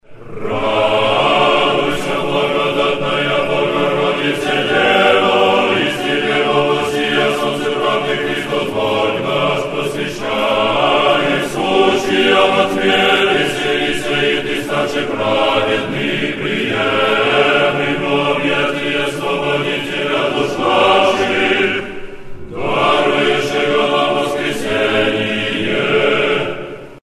Tropar-sretenie_gospodne.mp3  (размер файла: 425 Кб, MIME-тип: audio/mpeg ) Тропарь Сретения Господня История файла Нажмите на дату/время, чтобы просмотреть, как тогда выглядел файл.